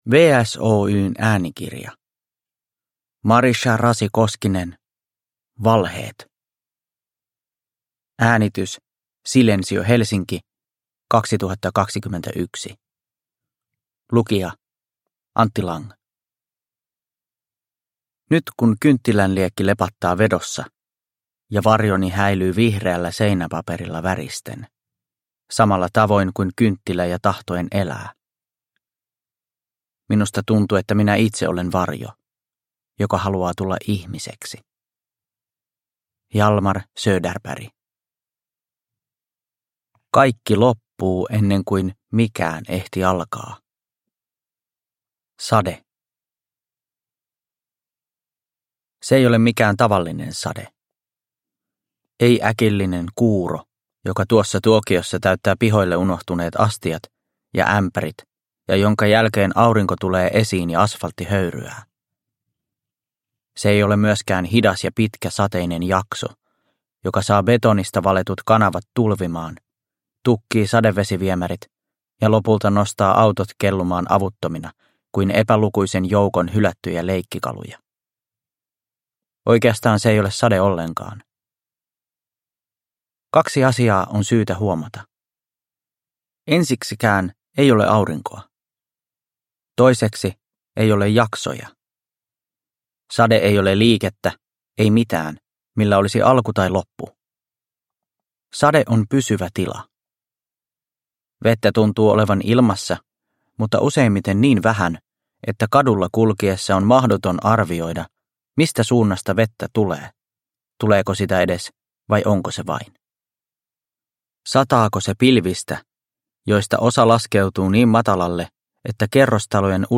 Valheet (ljudbok) av Marisha Rasi-Koskinen